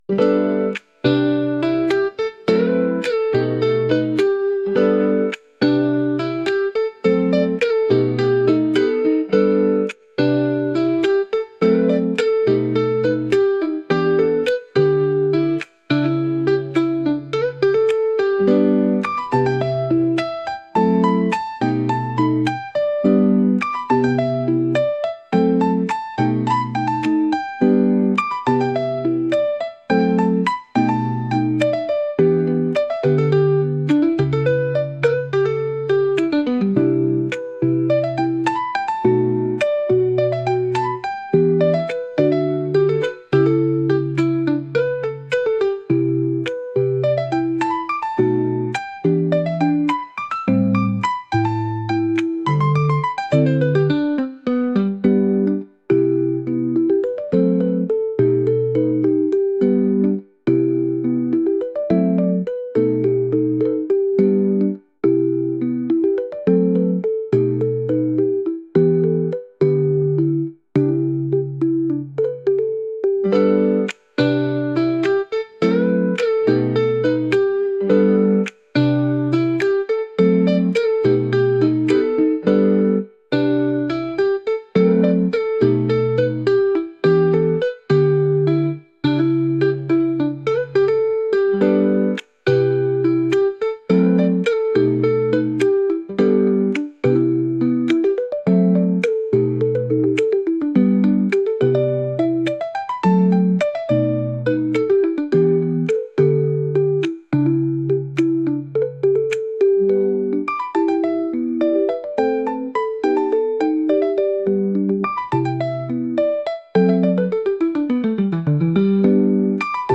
「大人な雰囲気」